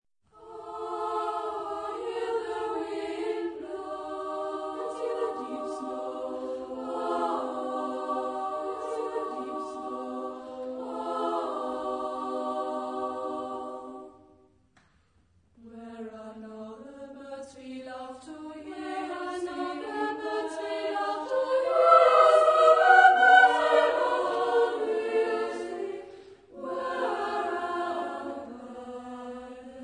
Genre-Style-Forme : Cycle ; Pièce chorale ; Profane
Type de choeur : SSAA  (4 voix égales de femmes )
Tonalité : libre